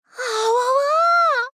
贡献 ） 协议：Copyright，其他分类： 分类:雪之美人语音 您不可以覆盖此文件。